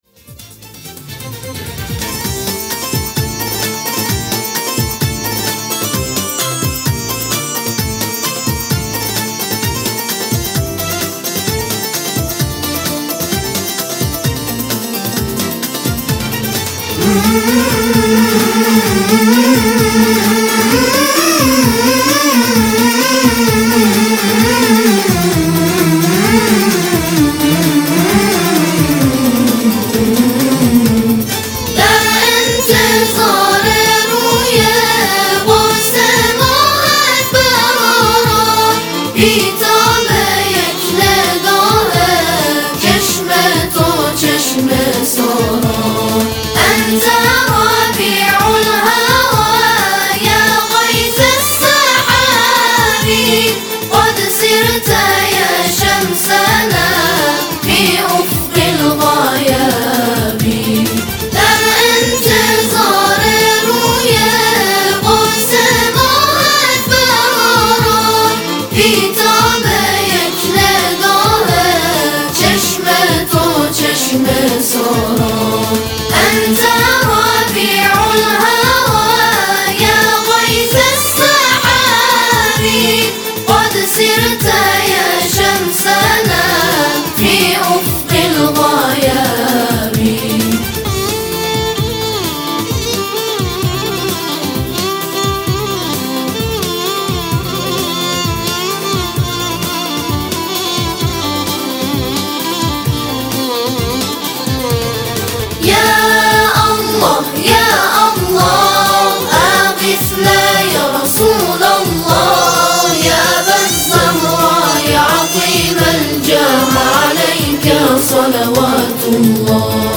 همخوانی موزیکال فارسی - عربی